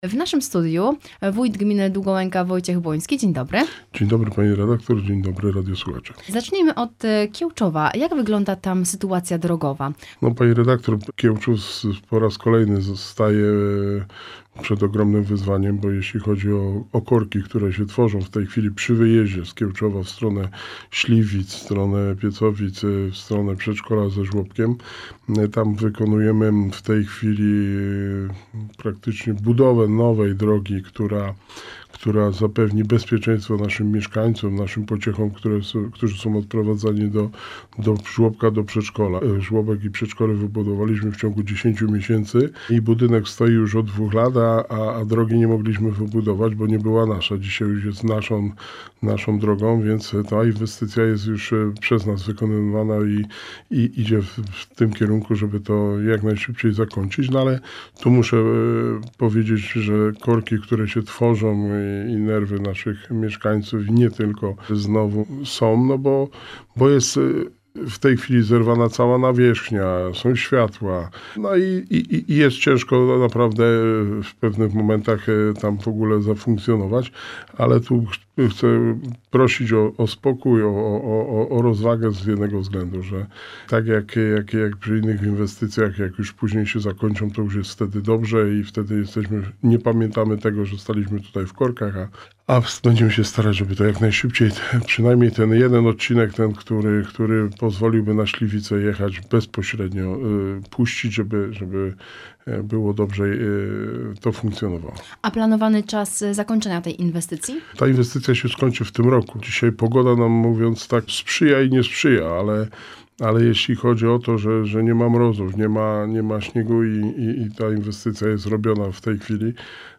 W rozmowie Radia Rodzina z wójtem Gminy Długołęka – Wojciechem Błońskim porozmawialiśmy o aktualnych i przyszłych inwestycjach. Poruszamy także tematy rozpoczętej rozbudowy szkoły Brzezia Łące, a także szkoły w Wilczycach.